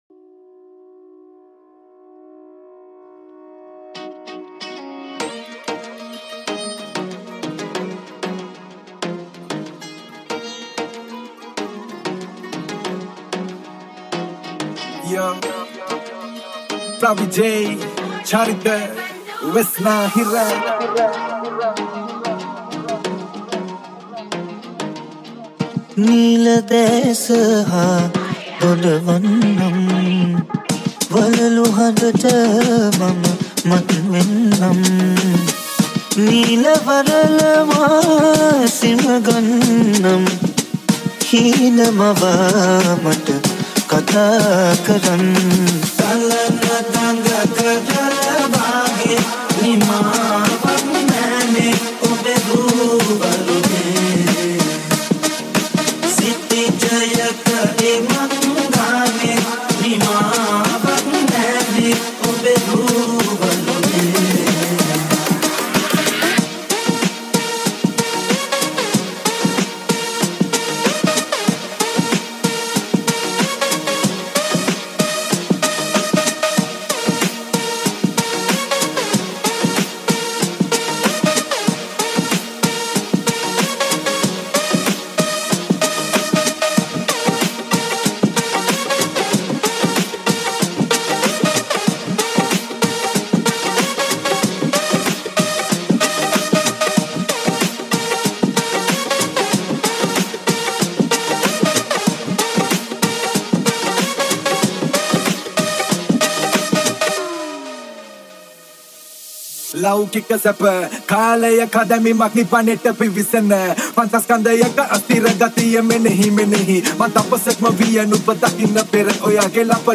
High quality Sri Lankan remix MP3 (4.6).